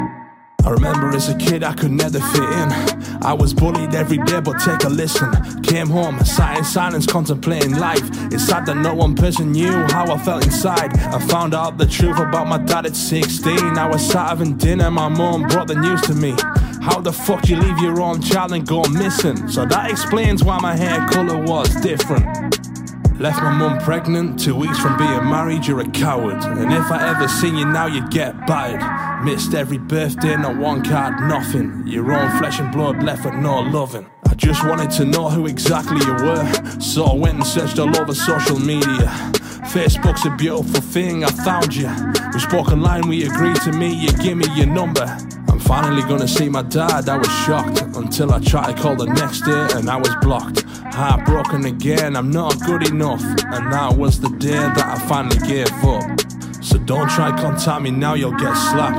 RAPPER SPEAKS ON DEADBEAT DAD sound effects free download